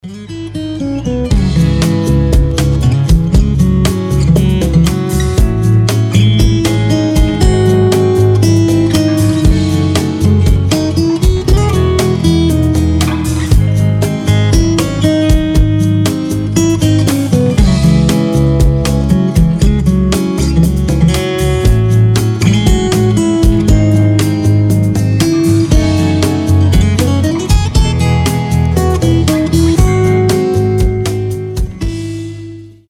• Качество: 320, Stereo
гитара
спокойные
без слов
акустика